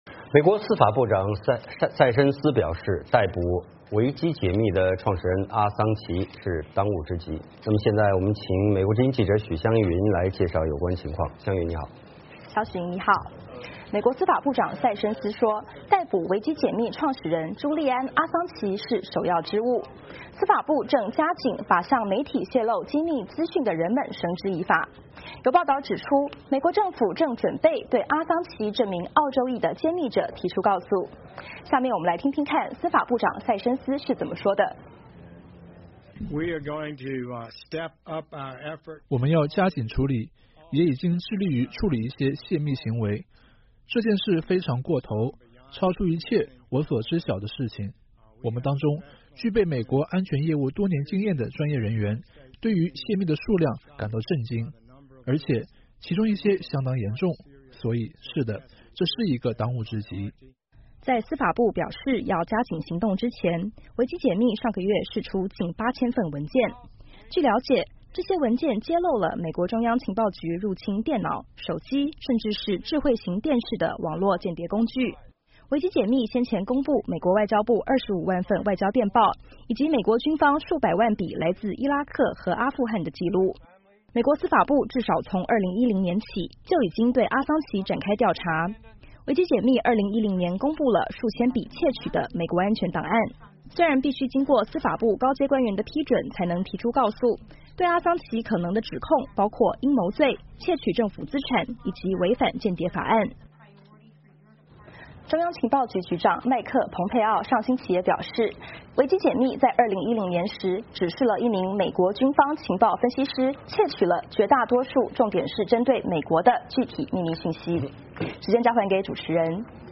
VOA连线：塞申斯：逮捕维基解密创始人阿桑奇乃当务之急